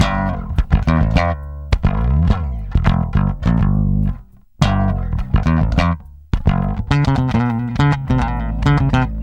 Loops de baixo 42 sons